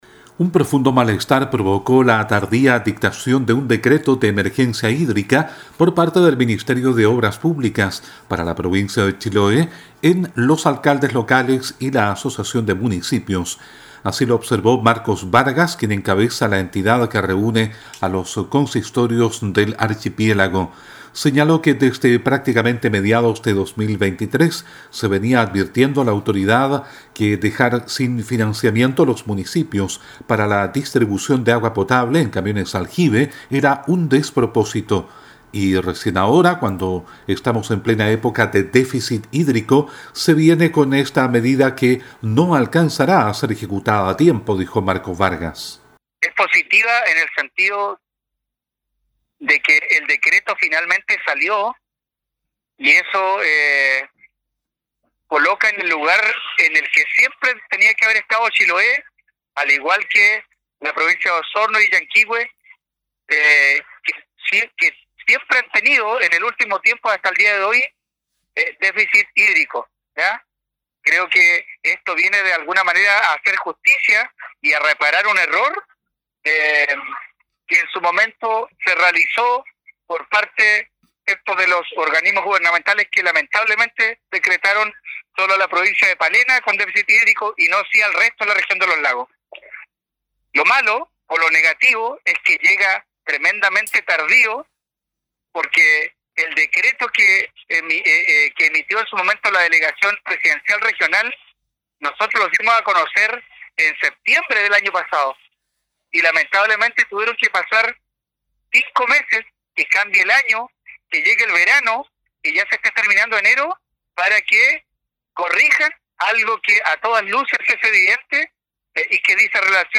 En Chiloé hubo reacción inmediata a esta declaratoria de crisis hídrica por parte del gobierno. A continuación el reporte